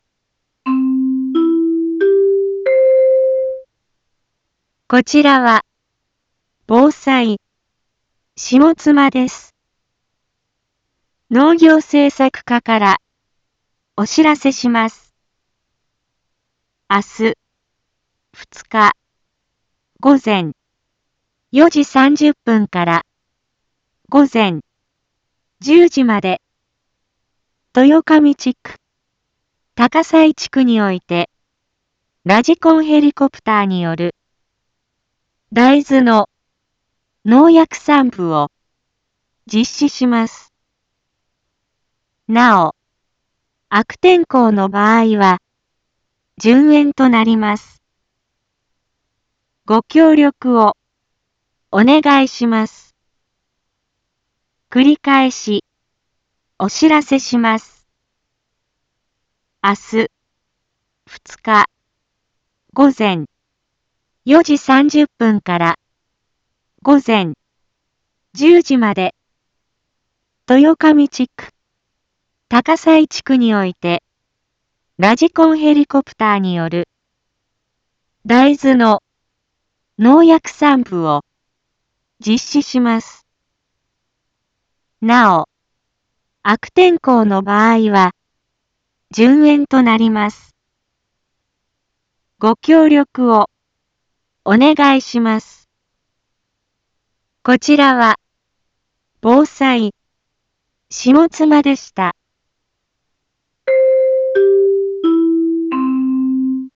Back Home 一般放送情報 音声放送 再生 一般放送情報 登録日時：2025-09-01 12:32:04 タイトル：大豆の無人ヘリによる空中防除 インフォメーション：こちらは、ぼうさいしもつまです。